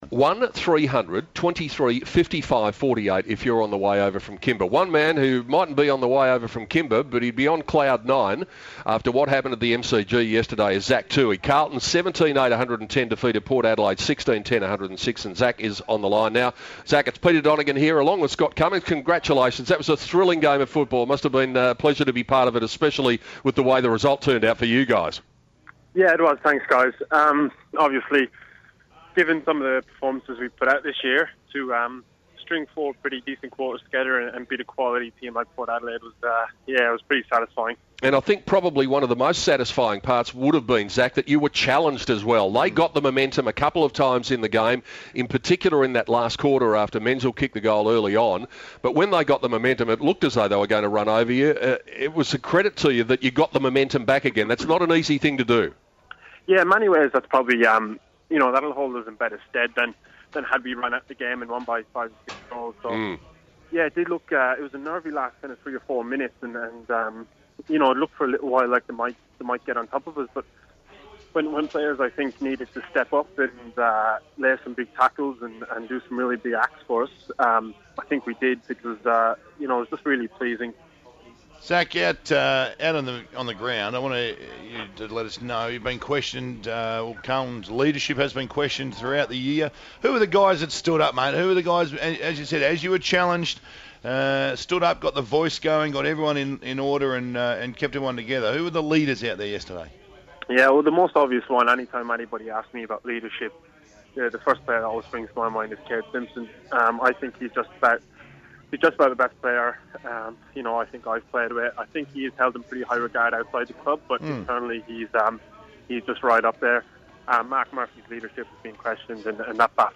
Carlton defender Zach Tuohy talks on Crocmedia's AFL Live Radio after the Blues' pulsating four-point win over the Power.